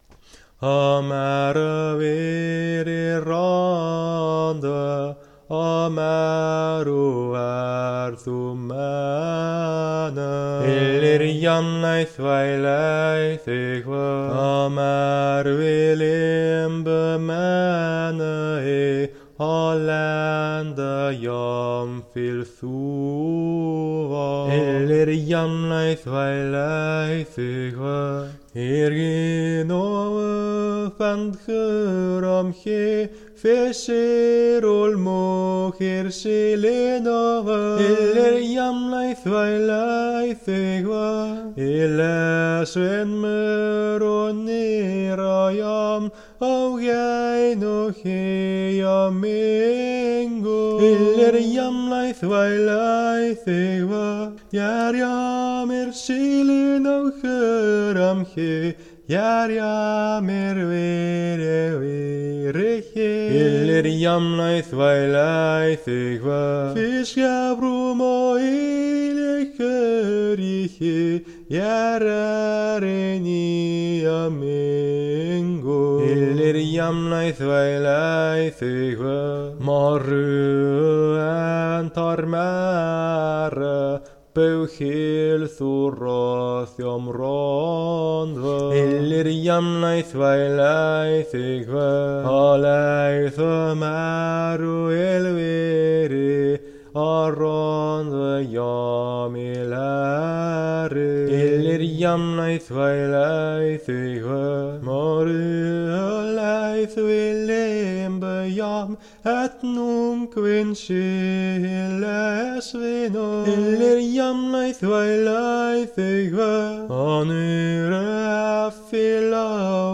Hymn św. Nektariusza po salladorsku